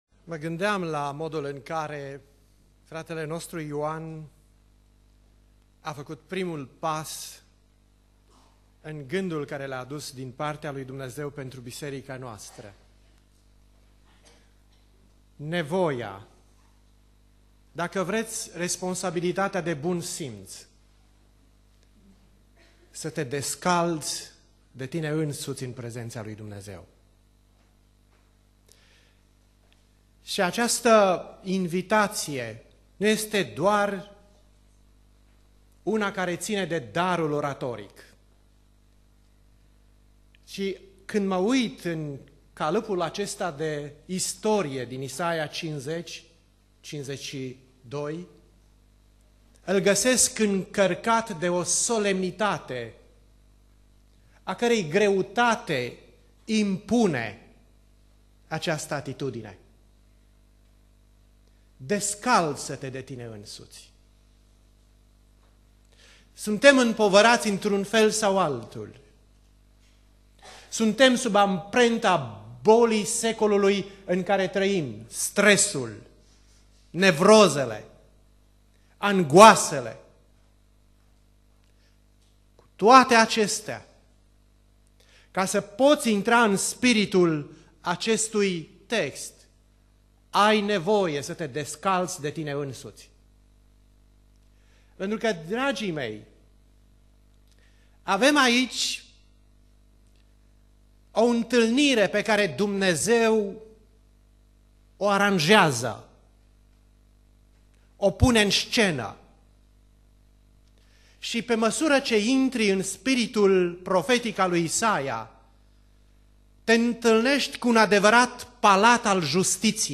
Predica Aplicatie - Isaia 50-52